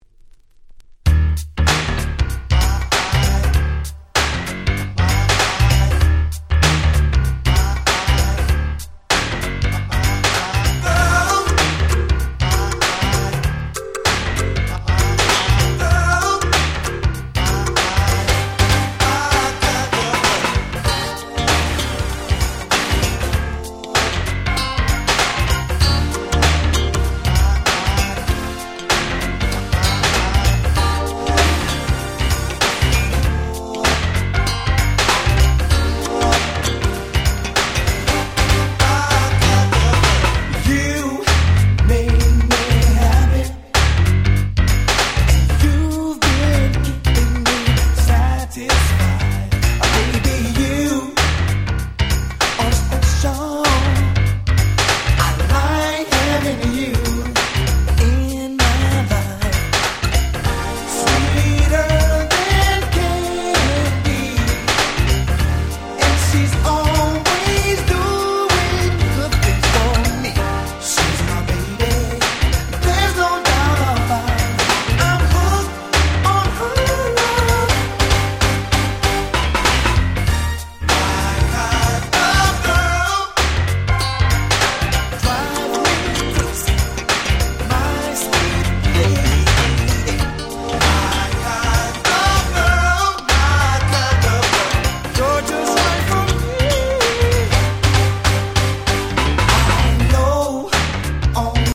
90' Very Nice R&B / New Jack Swing !!
緩いSwing Beatに甘い歌声、日本人受けバッチリな明るいメロディー！！
NJS ハネ系 ニュージャックスウィング